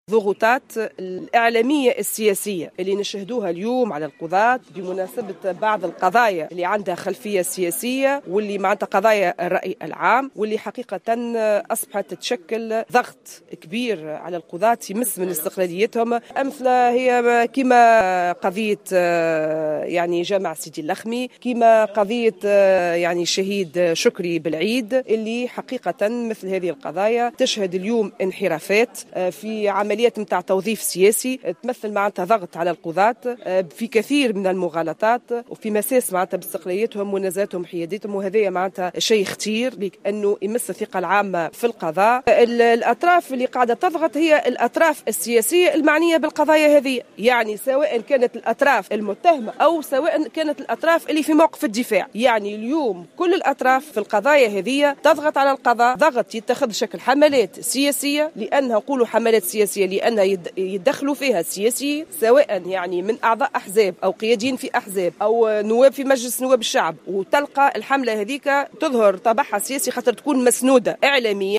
La présidente de l’Association des magistrats tunisiens (AMT), Raoudha Karafi, a affirmé le 7 novembre 2015, dans une déclaration à la presse en marge de la tenue du Conseil national du Bureau exécutif de l'AMT, que les magistrats subissent une pression politique et médiatique concernant certaines affaires à l’instar de l’affaire Chokri Belaïd ou celle concernant la mosquée Sidi Lakhmi à Sfax.